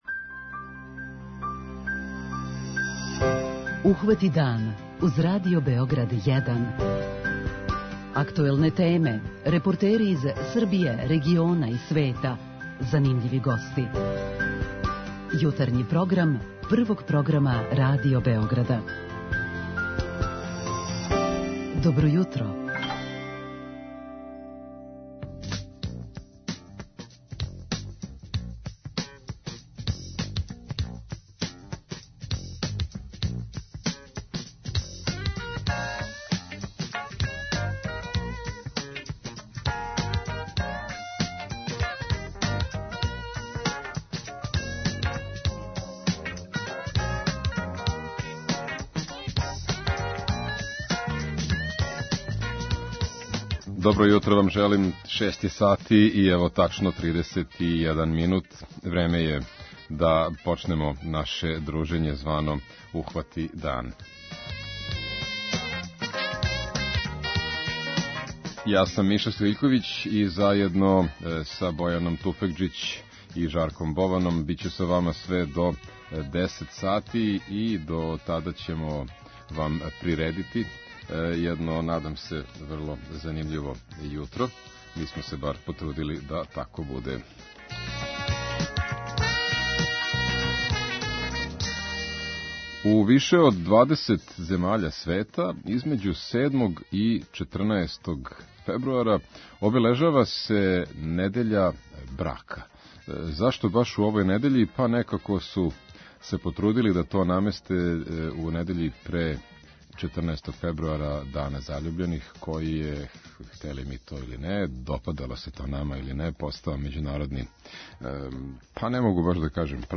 У више од 20 земаља света недеља од 7. до 14. фебруара обележева се Недеља брака, као увод у Дан заљубљених који је већ постао планетарни празник. Тим поводом у јутарњем програму мало се више бавимо овом темом.
Јутарњи програм Радио Београда 1!